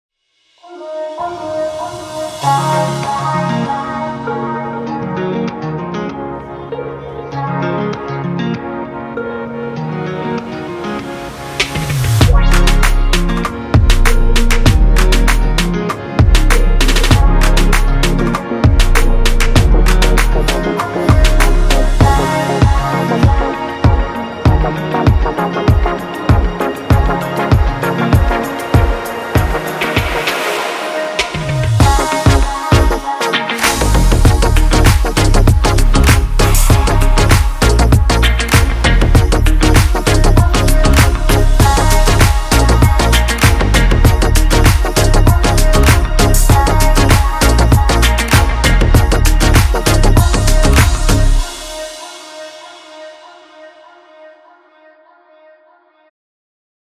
如果您正在寻找与Future Bass合成器和Housey节拍融合的吸引人的钩子，则此包适合您。